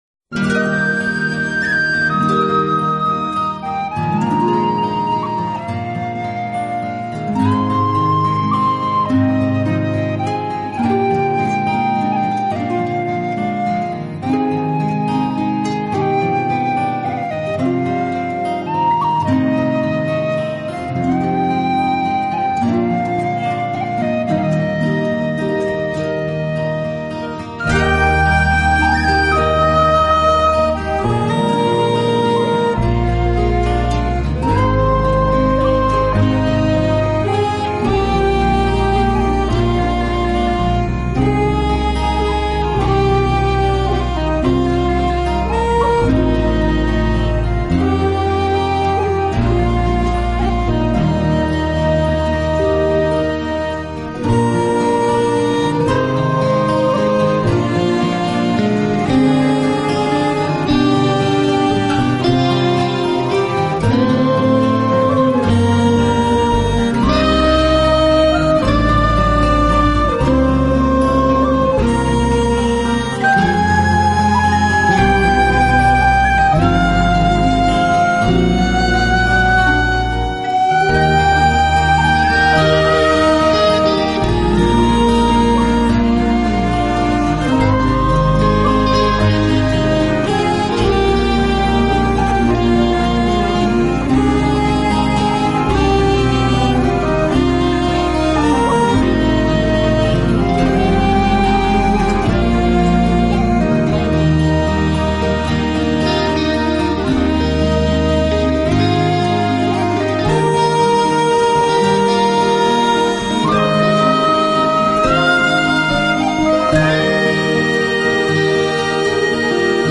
【风笛专辑】